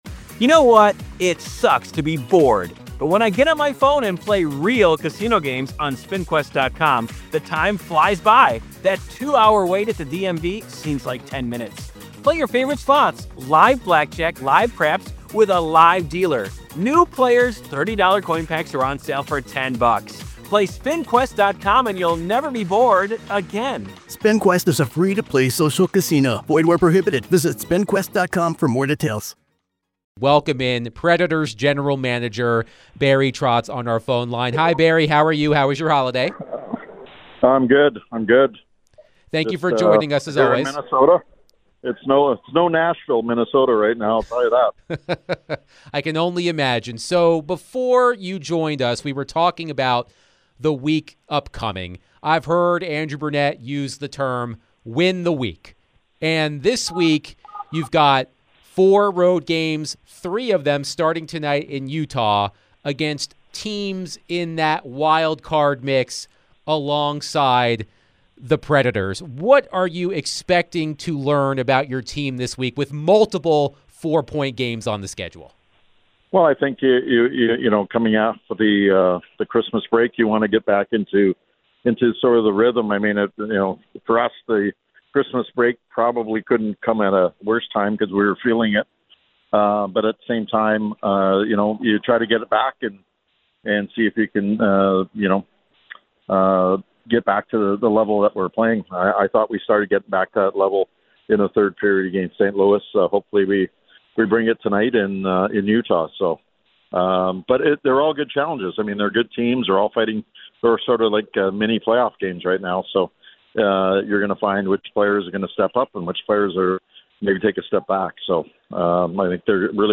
Nashville Predators General Manager Barry Trotz joins DVD for his weekly chat.